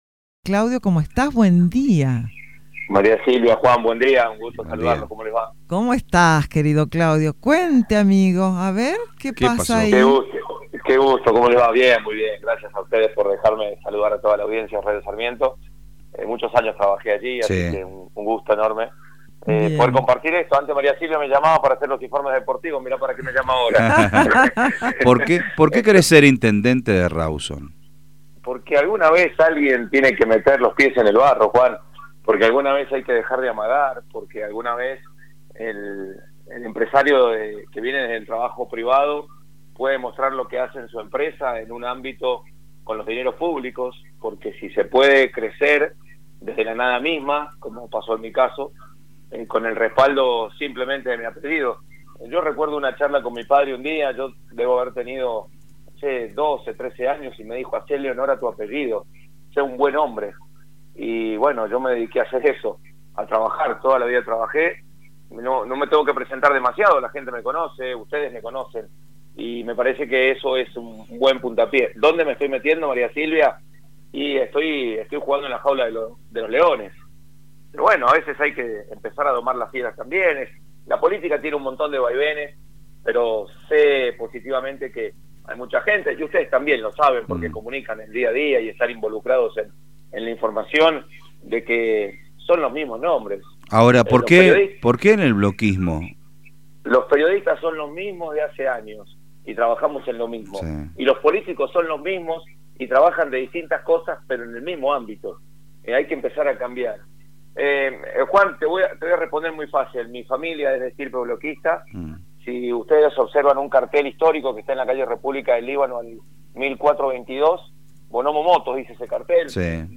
el reconocido periodista deportivo estuvo en los micrófonos de Radio Sarmiento para hablar sobre el tema.